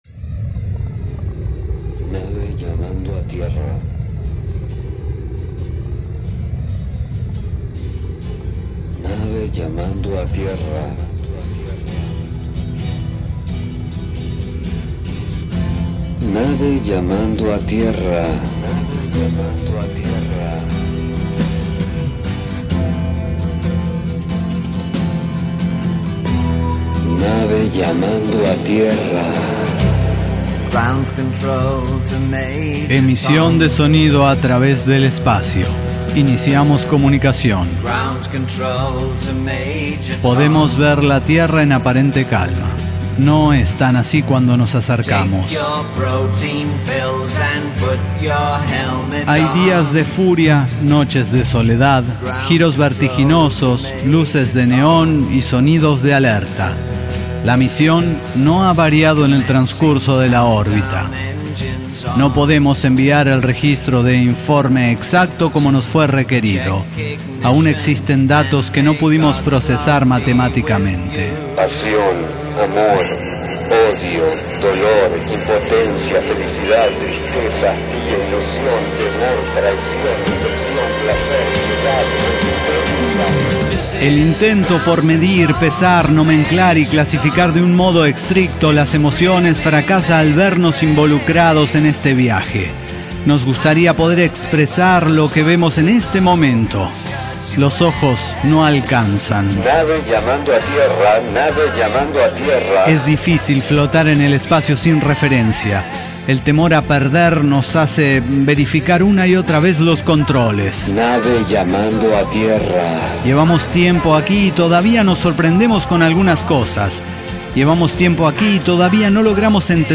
Resumen de la transmisión en vivo del 4 de marzo de 2017, celebrando los 18 años de ECDQEMSD y el inicio de la Temporada 19. Más de diez horas de Transmisión en Directo compiladas en este audio.